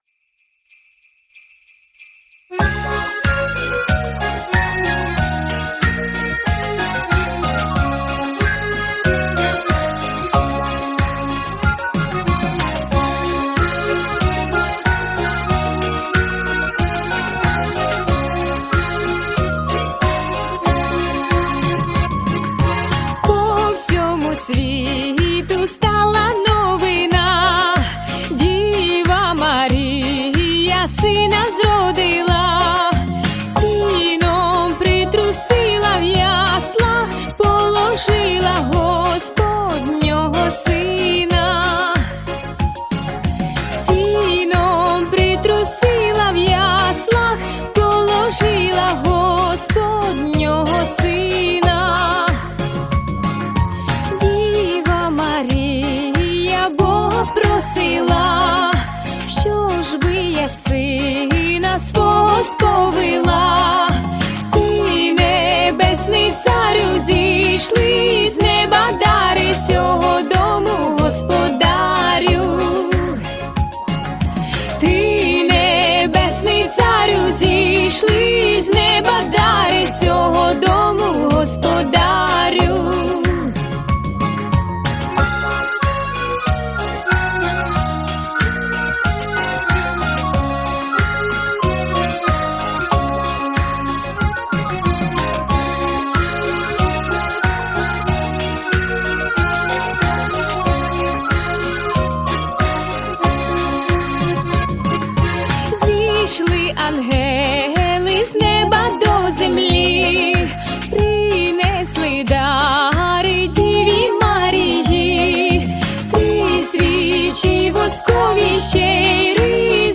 колядка